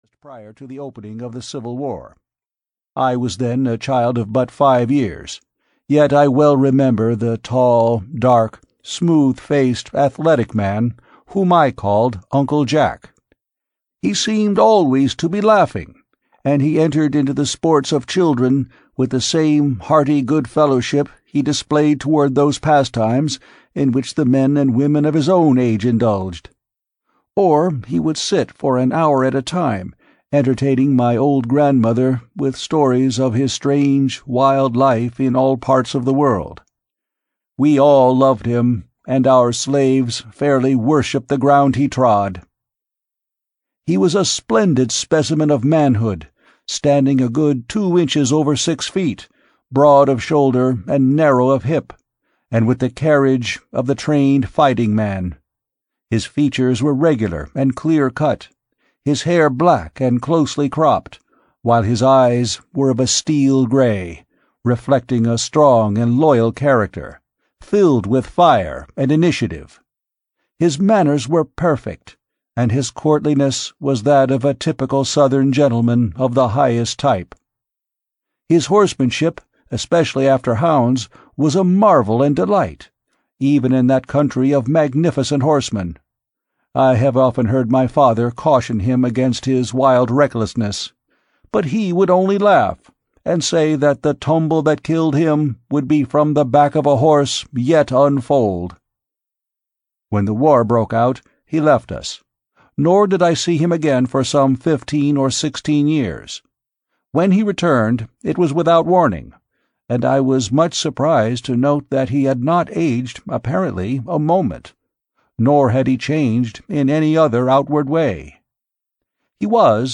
A Princess of Mars (EN) audiokniha
Ukázka z knihy